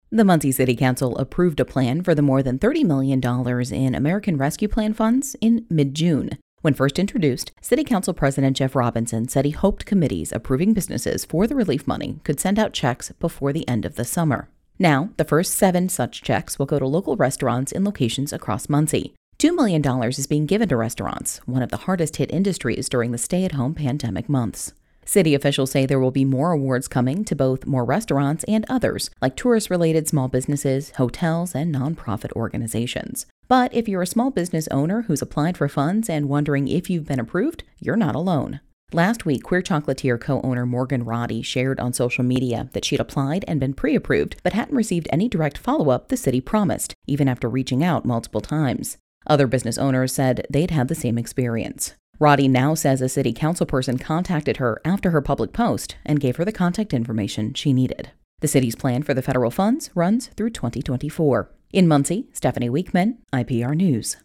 IPR News